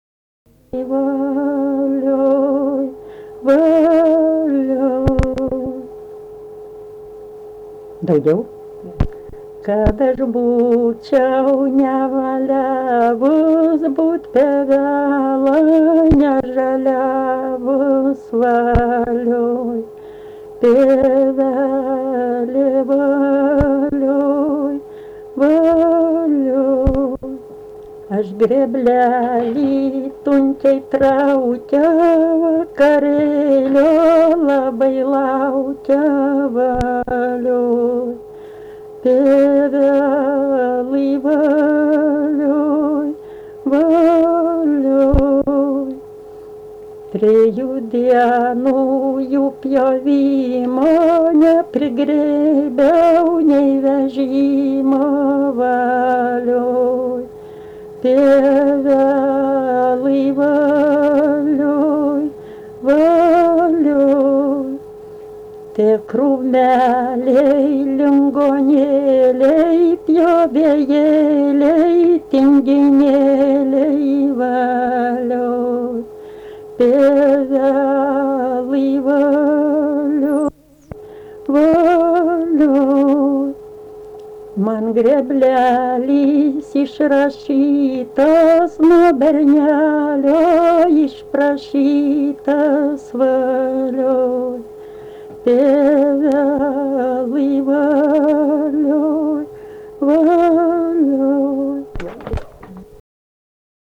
daina, kalendorinių apeigų ir darbo
Čypėnai
vokalinis